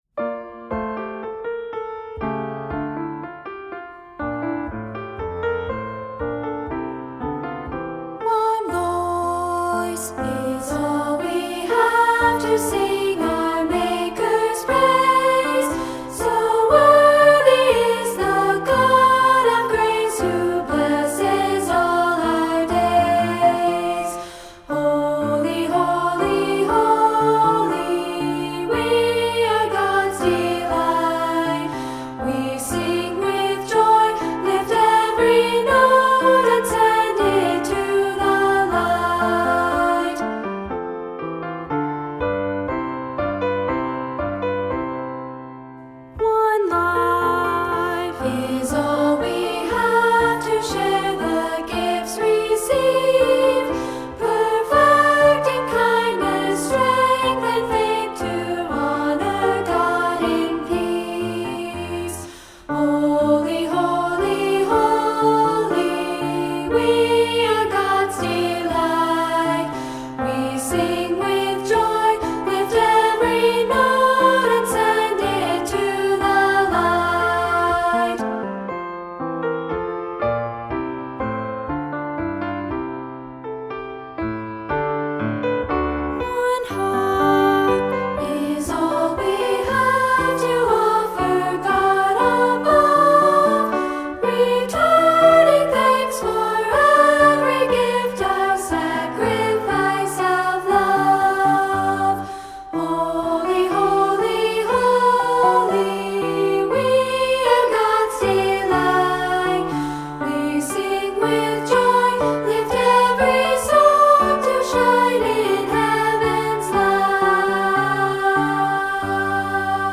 Unison with piano